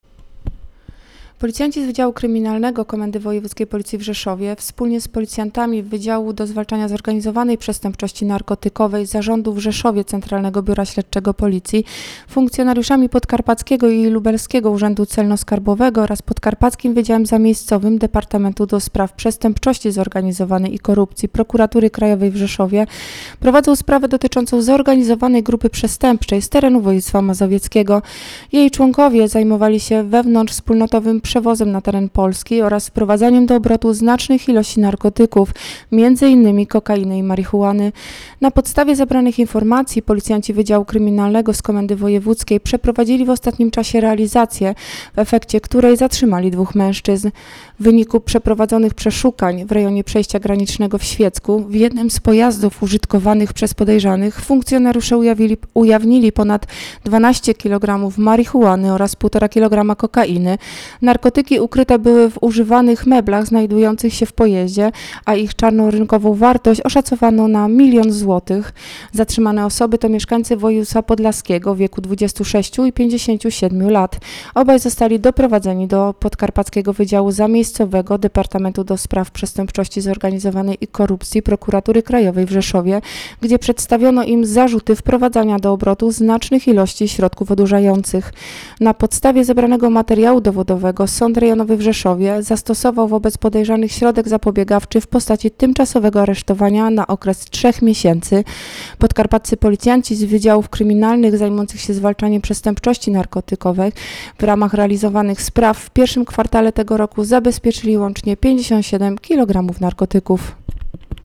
Nagranie audio Policjanci przejęli ponad 13 kilogramów narkotyków - mówi